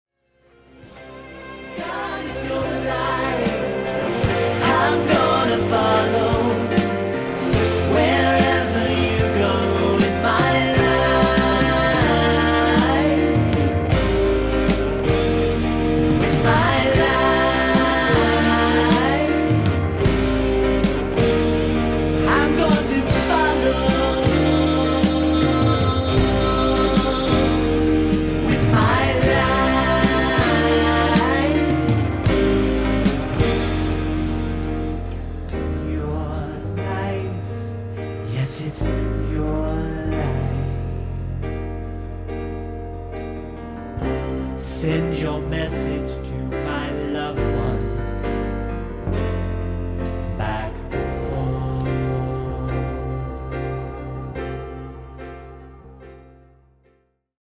Piano/Keyboard/Hammon B3 Organ & Lead Vocals